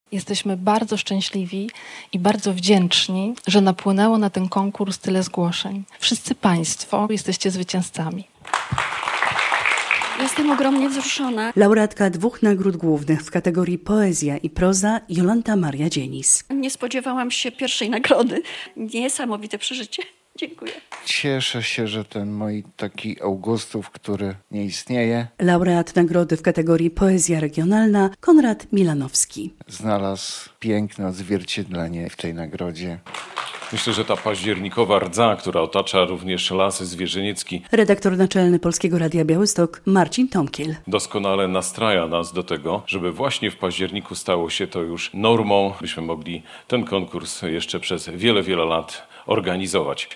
Finał odbył się w Studiu Rembrandt Polskiego Radia Białystok.